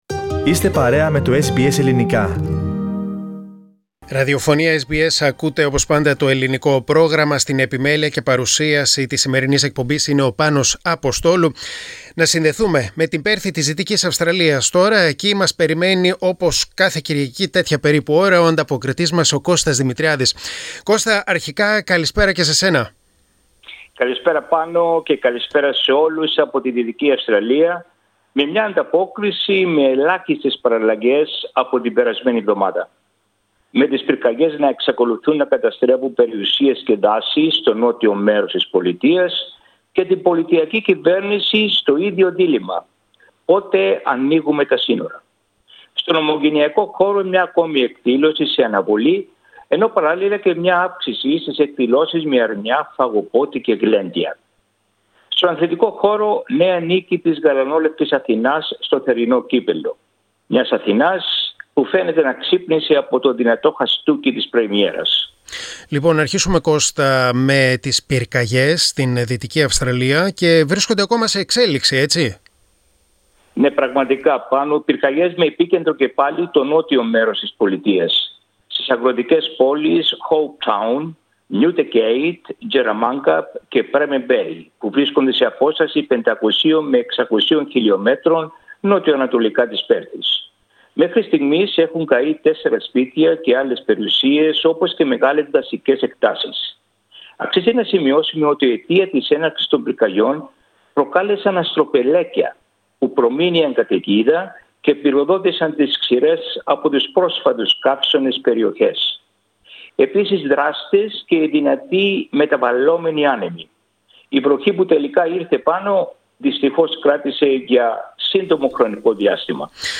Πυρκαγιές και πανδημία στην εβδομαδιαία ανταπόκριση από την Δυτική Αυστραλία.